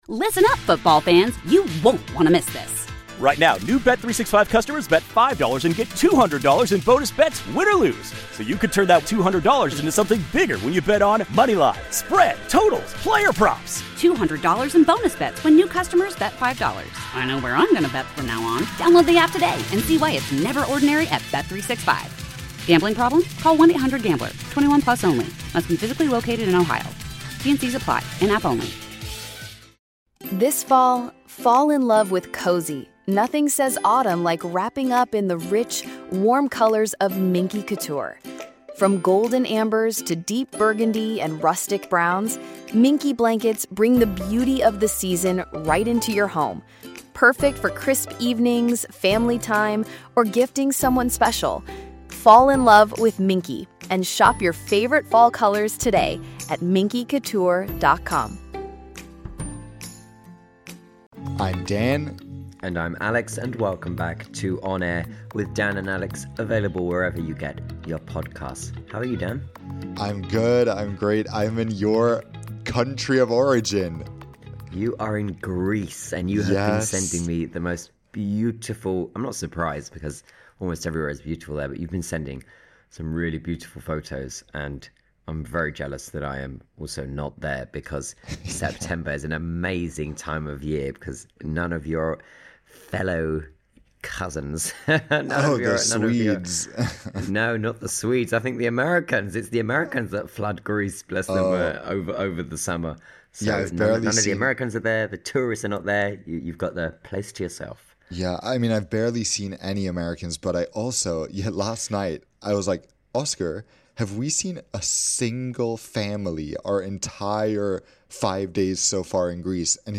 From industry shake-ups to the politics shaping how we fly, expect sharp insight, lively debate, listener Q&A, and a whole lot of fun along the way.